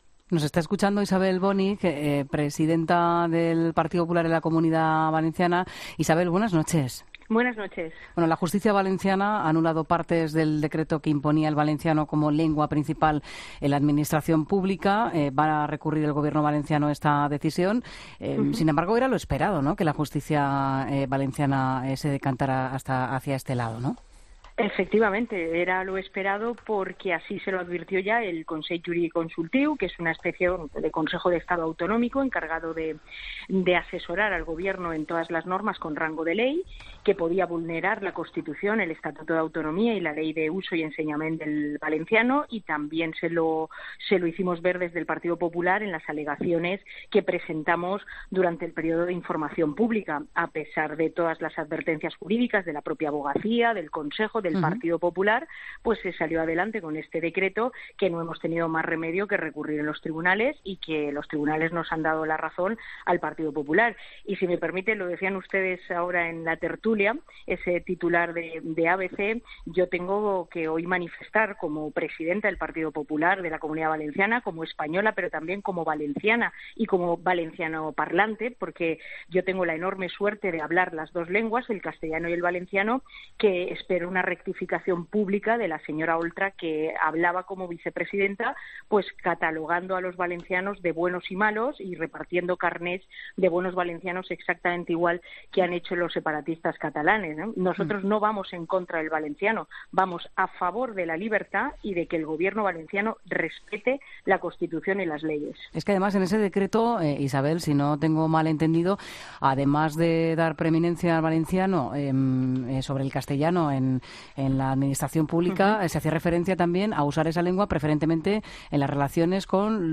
La presidenta del PP de Valencia, Isabel Bonig, ha hablado en ' La Linterna ' de las inminentes primarias de su partido. No quiere mostrar apoyo públicamente a ninguno de los candidatos a la presidencia.